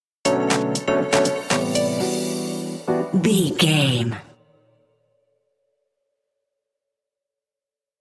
Dorian
groovy
uplifting
driving
energetic
drum machine
synthesiser
funky house
electronic
upbeat
instrumentals
funky guitar
synth bass